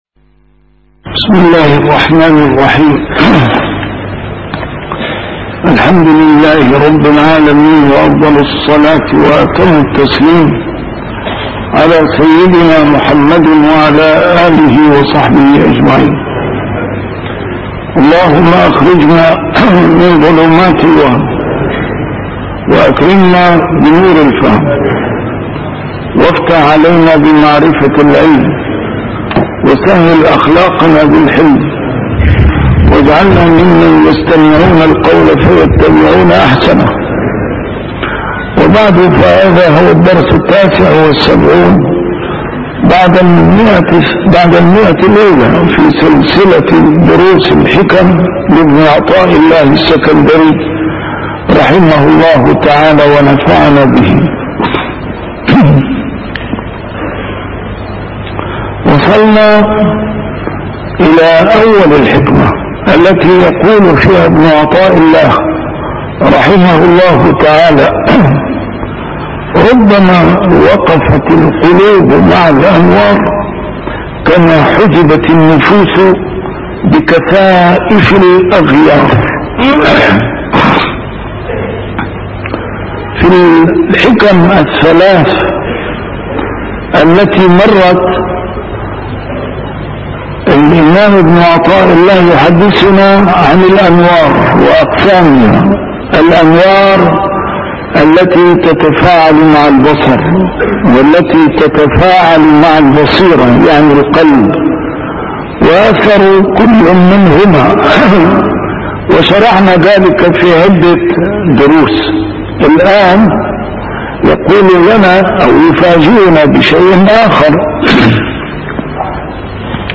A MARTYR SCHOLAR: IMAM MUHAMMAD SAEED RAMADAN AL-BOUTI - الدروس العلمية - شرح الحكم العطائية - الدرس رقم 178 شرح الحكمة 154